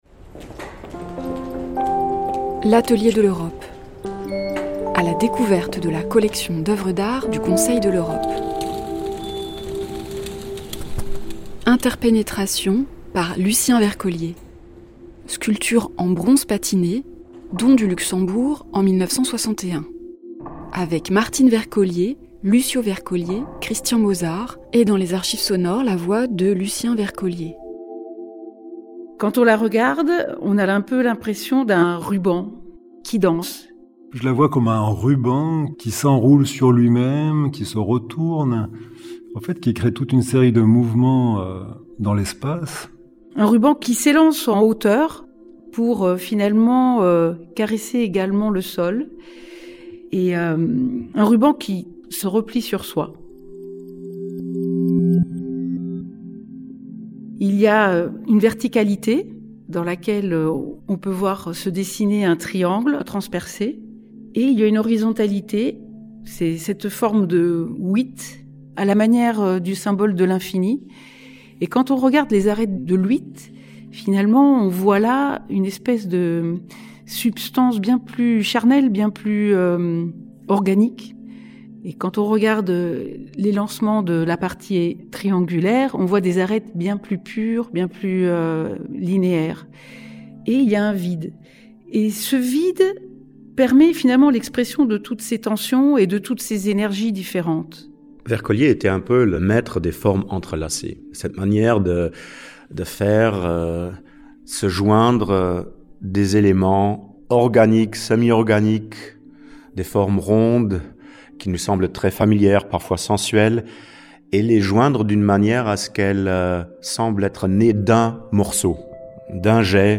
historien de l’art et directeur artistique